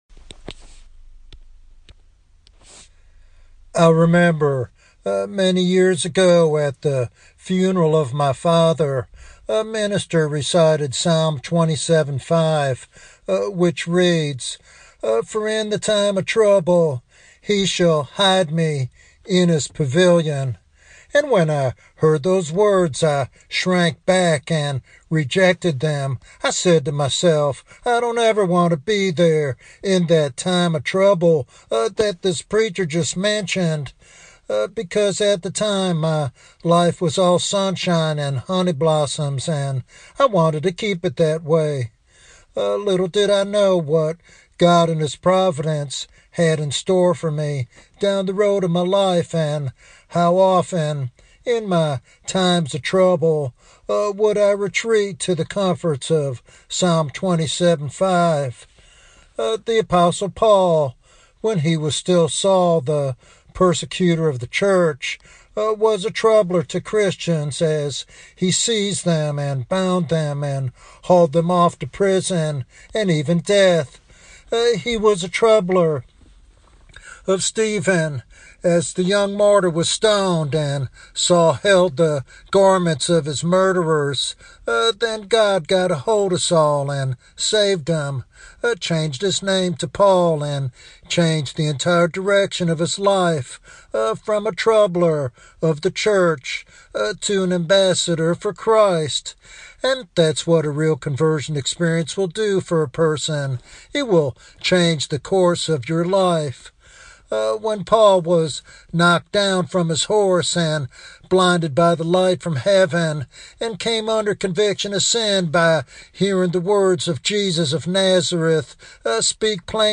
This sermon serves as both a warning against half-hearted faith and an encouragement to trust God's providence in difficult times.